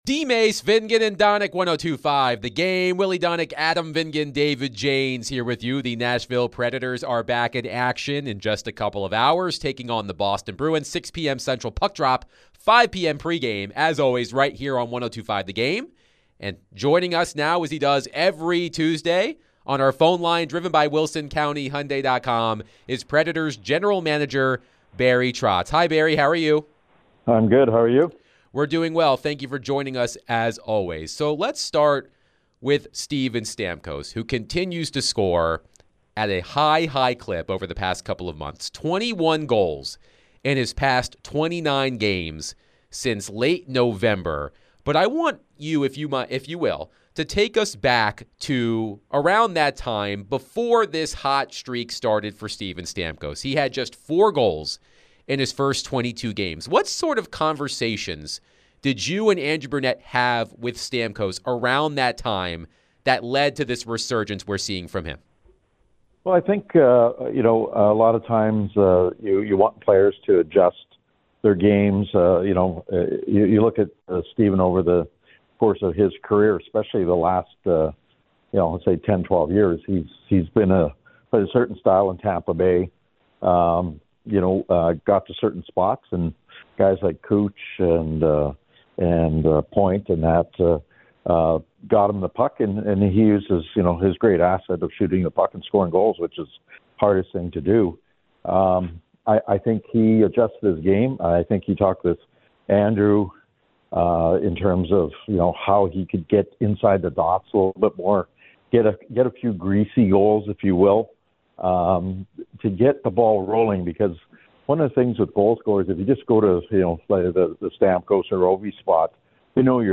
Nashville Predators General Manager Barry Trotz joined DVD for his weekly chat